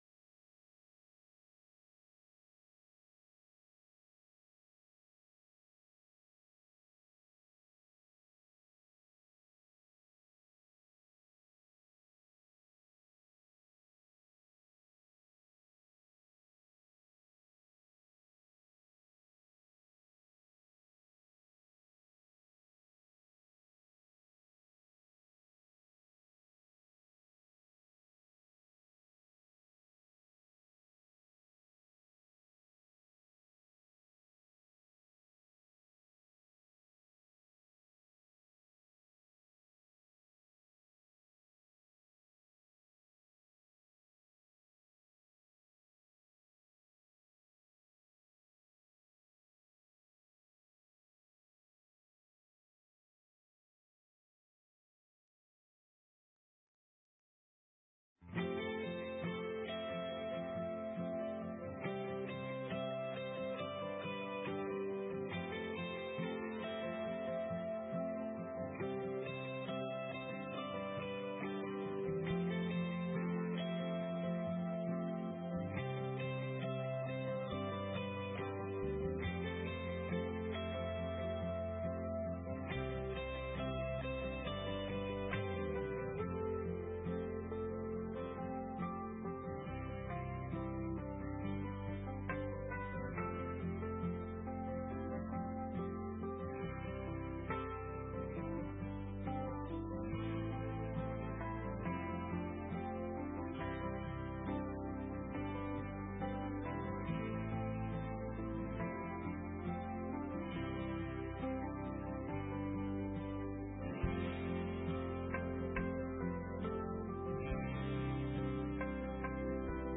The worship service begins 15 minutes into the recordings